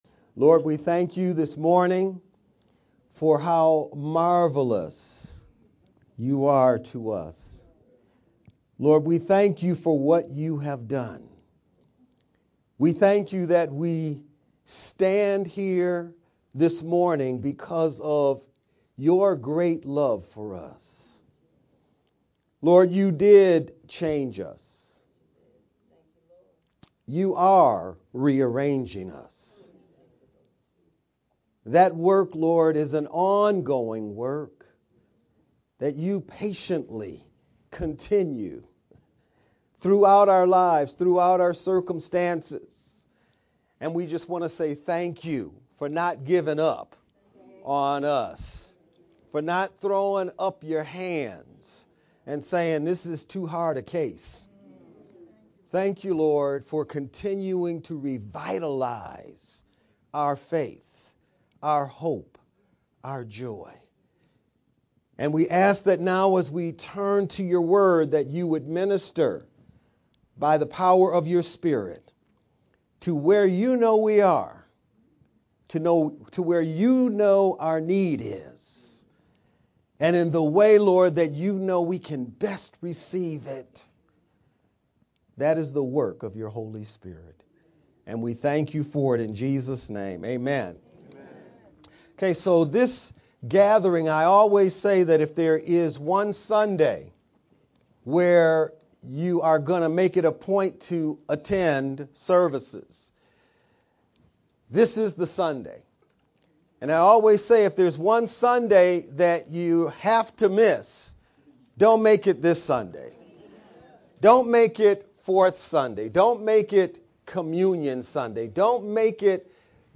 VBCC-Oct-27th-sermon-only_Converted-CD.mp3